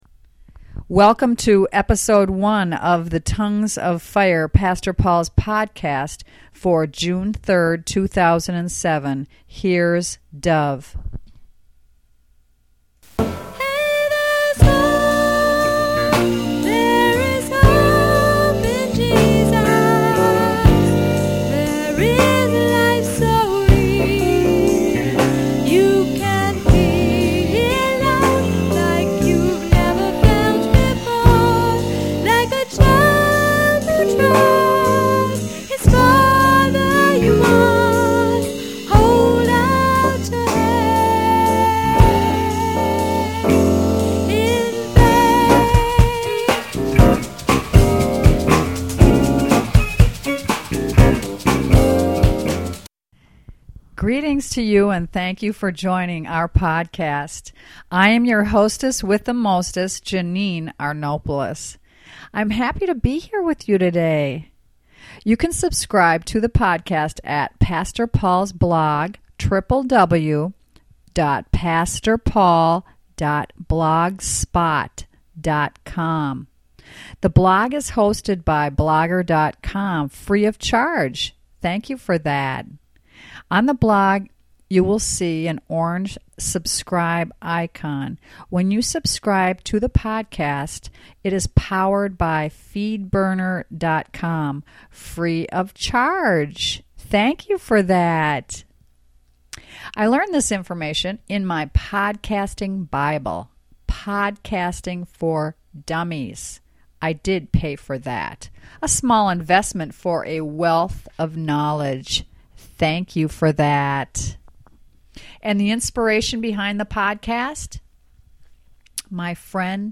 Coming to you from Spirited North Minneapolis, Minnesota, USA